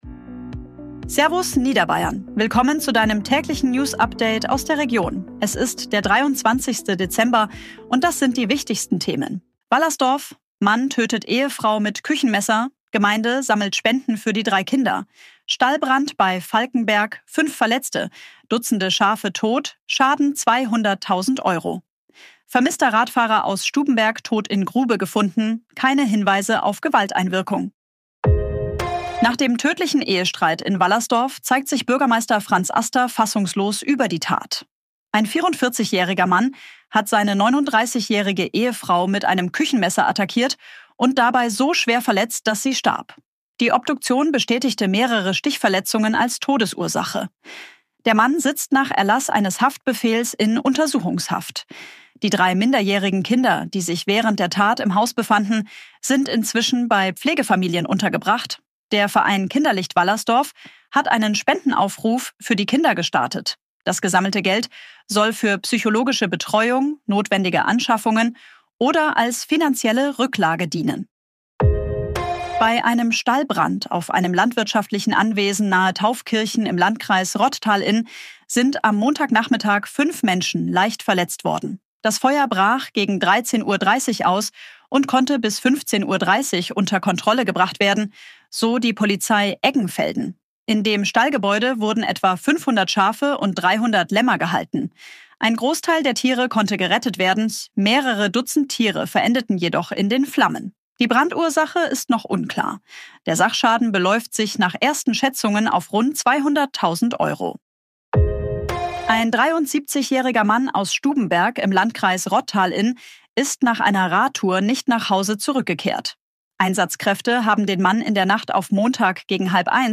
Tägliche Nachrichten aus deiner Region
Unterstützung künstlicher Intelligenz auf Basis von redaktionellen